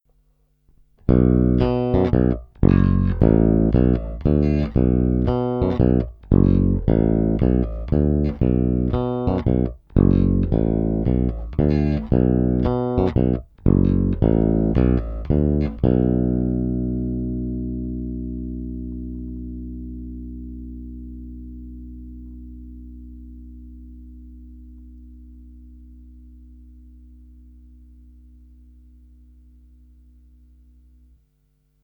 I přestože jsou na base půl roku staré struny, což u Elixirů v zásadě nic neznamená, je slyšet, že nové snímače mají o něco mohutnější basy a brilantnější výšky, než ty původní mexické.
Oba snímače – Super 55